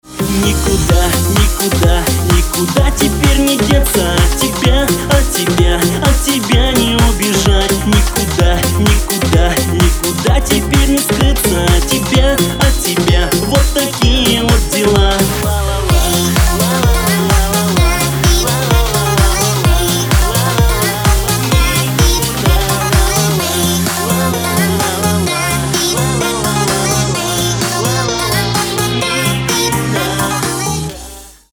• Качество: 320, Stereo
поп
dance
vocal